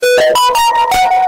Сamsung bildirim sesi bass boosted - Vsesam
Bu, bası güçlendirdiğimiz Samsung bildirim sesidir ve aşağıdaki bağlantıyı kullanarak tamamen ücretsiz olarak indirebilirsiniz.
Bundan sonra, standart ses sizi tatmin etmiyorsa hoşunuza gidecek bir bas efekti elde edeceksiniz.